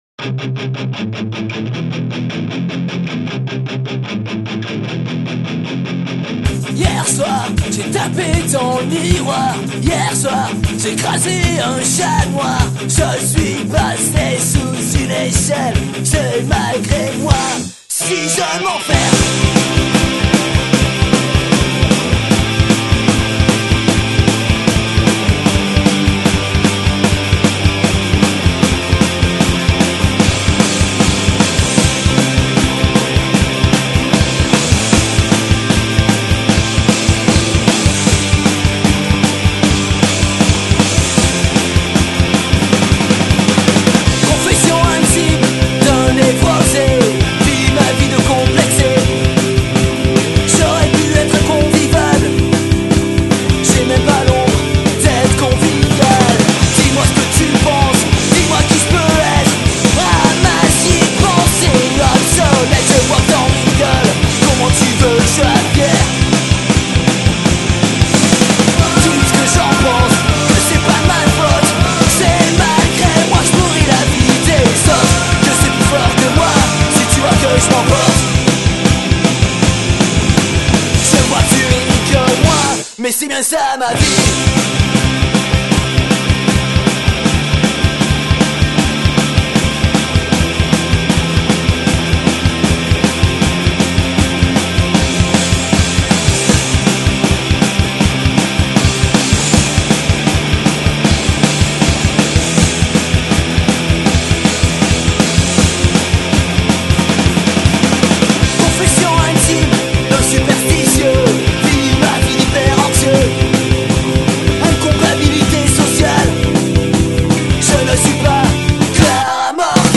Punk Rock